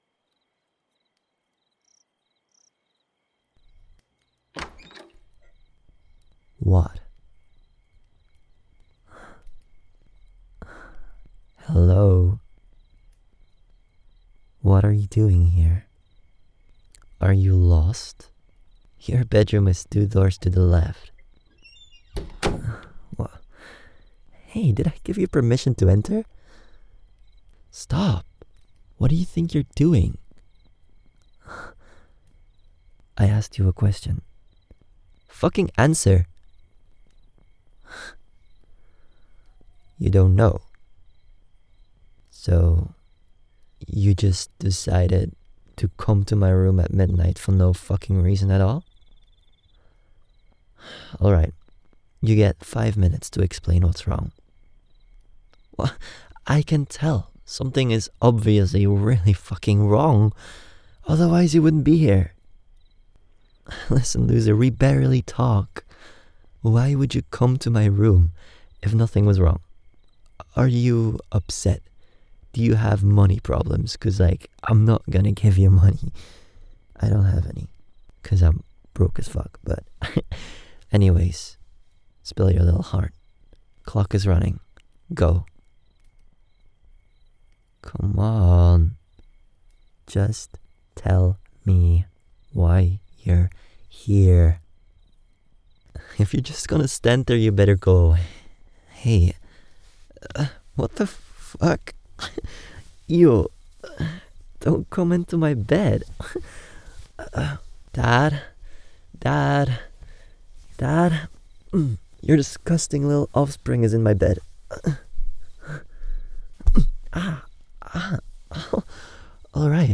Hope you enjoy this type of platonic sibling love roleplay!